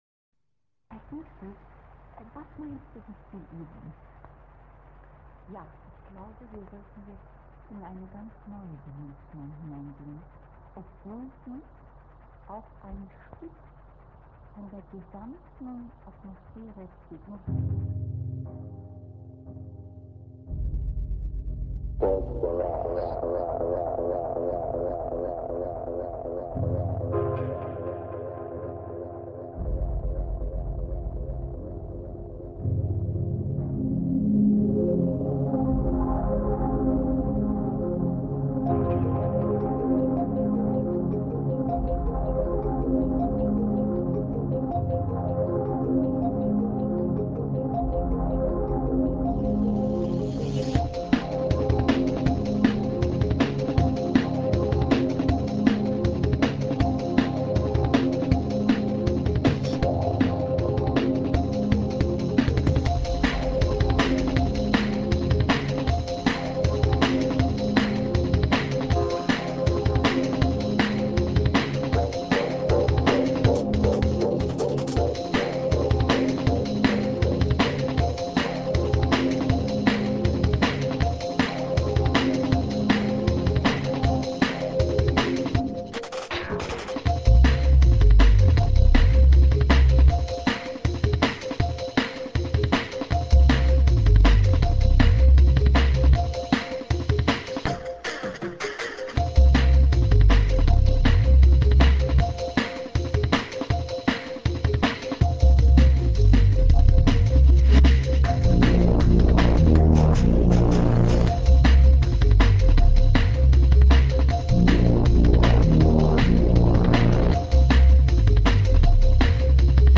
Dub, Downbeat und Drum&Bass